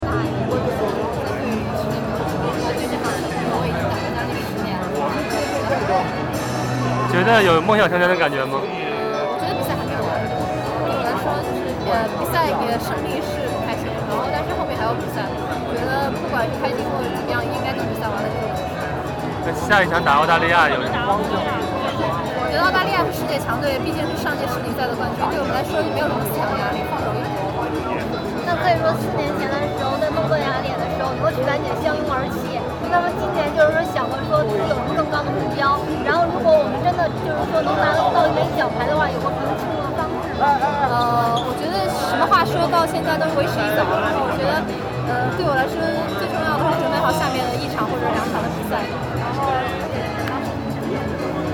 隋菲菲采访音频MP3(点击右键下载)
赛后，隋菲菲在混合区接受了媒体的采访。